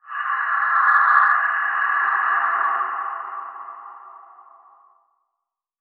8 bits Elements
Terror Noises Demo
TerrorNoise_27.wav